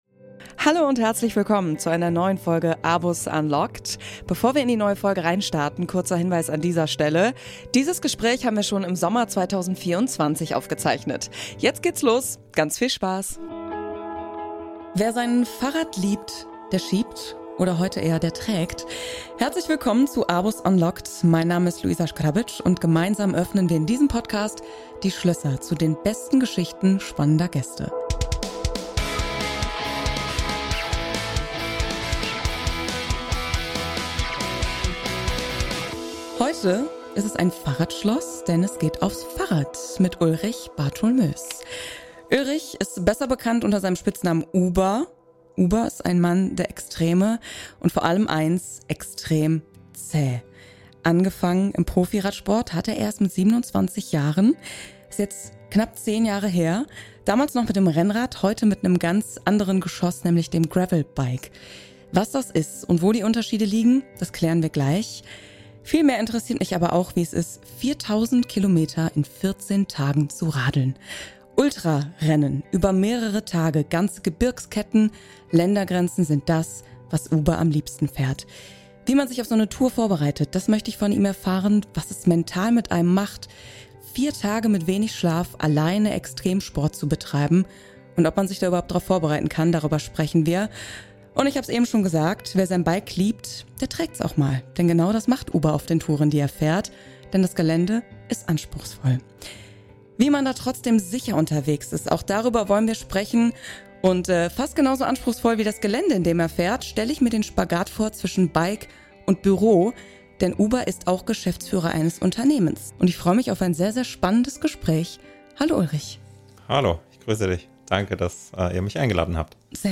Ein Gespräch über die Faszination des Gravel Bikings, das Erleben der Natur abseits befestigter Wege und darum, viele Stunden – manchmal bis zu 12 am Stück – allein mit sich und seinen Gedanken unterwegs zu sein.